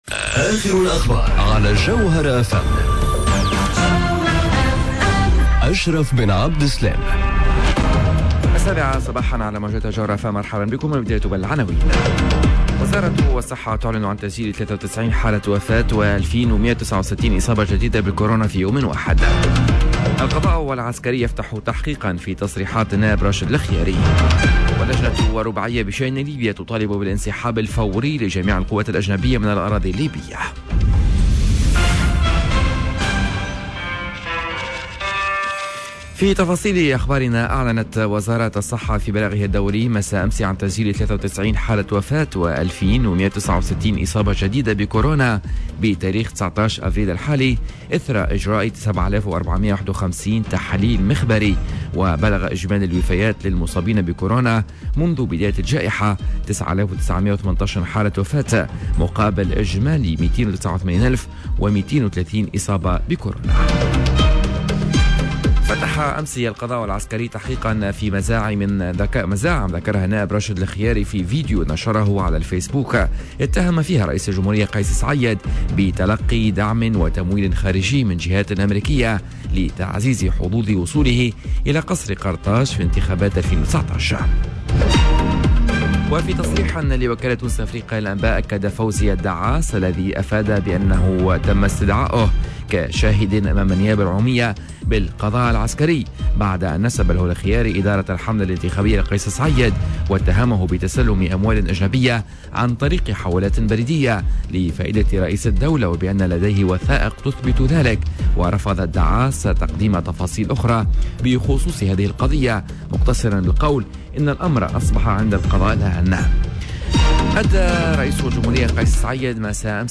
نشرة أخبار السابعة صباحا ليوم الإربعاء 21 أفريل 2021